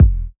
Urban Kick 03.wav